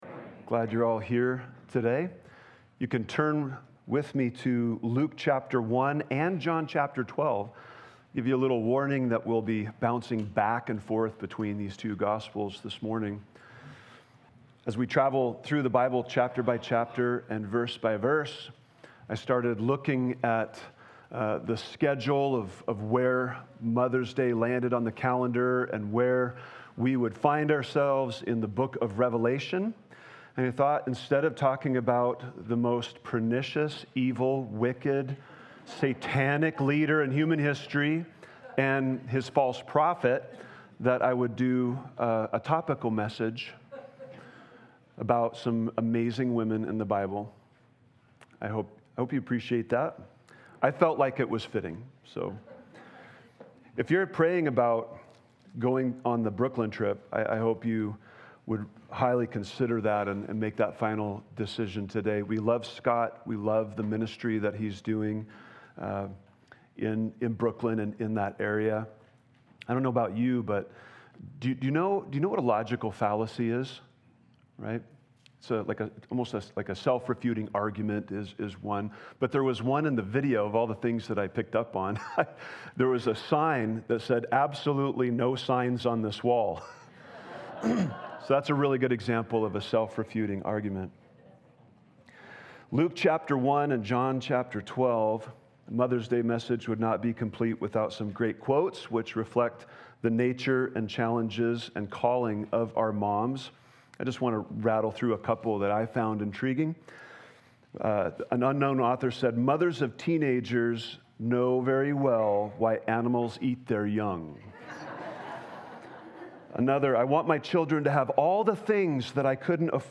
Listen to Sermon Scripture: Luke 1:26-38, Luke 10:38-42, John 12:1-8, John 20:11-16